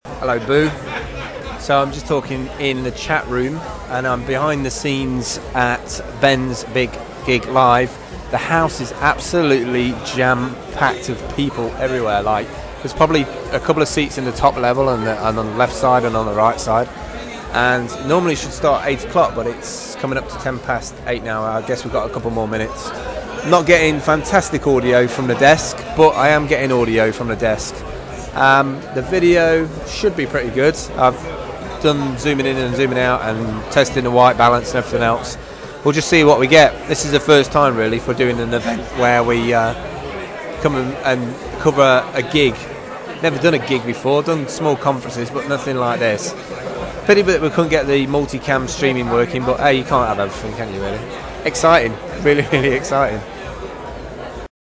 Live from Oxford